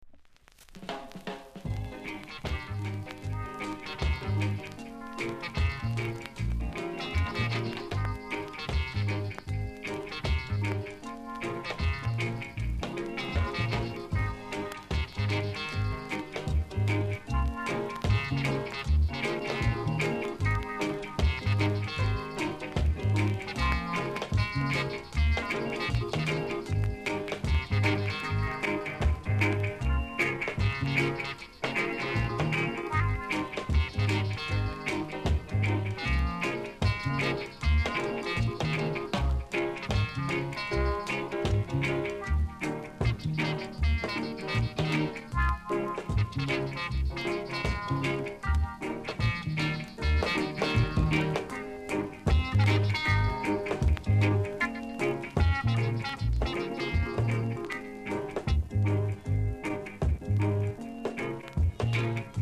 ※小さなチリ、パチノイズが少しあります。
RIDDIMのGOOD TUNE!!